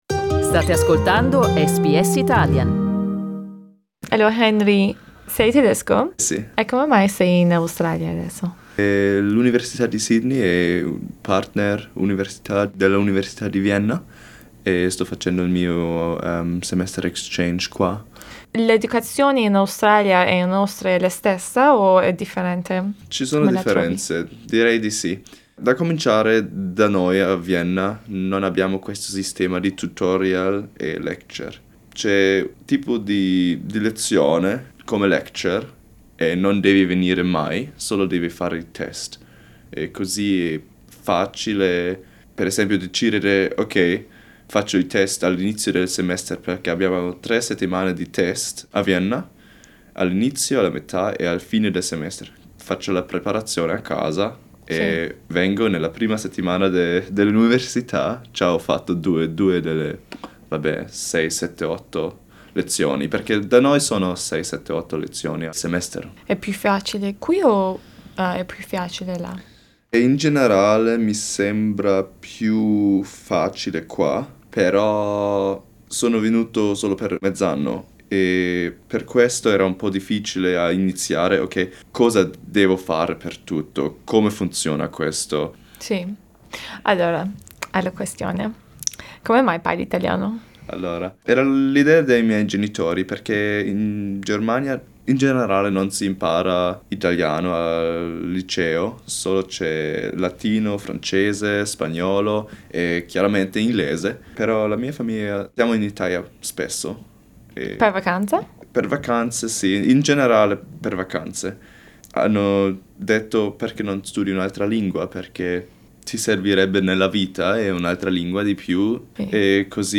The two young "Italophiles" met at the SBS Italian studios and "interviewed" each other.